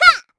Hilda-Vox_Attack2_kr.wav